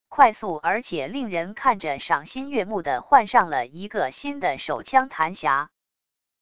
快速而且令人看着赏心悦目的换上了一个新的手枪弹匣wav音频